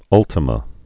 (ŭltə-mə)